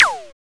CD-ROOM/Assets/Audio/SFX/laser2.wav at main
laser2.wav